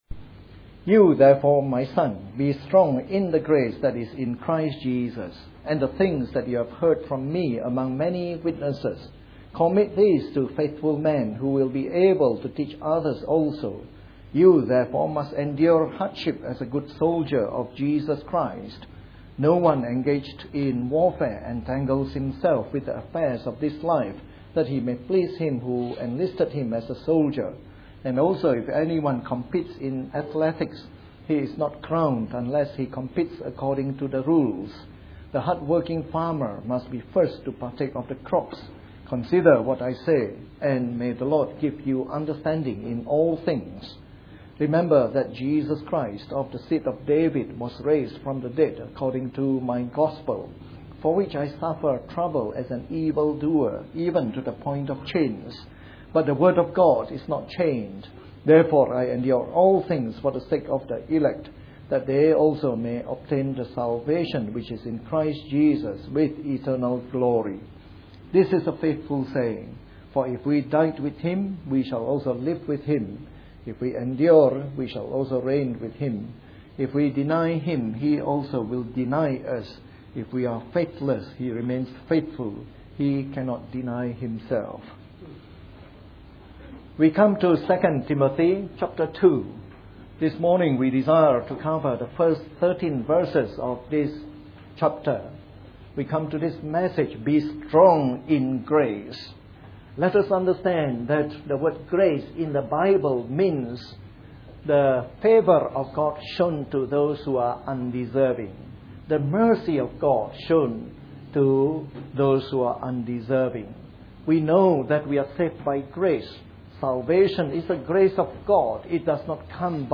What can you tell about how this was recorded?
A sermon in the morning service from our series on 2 Timothy.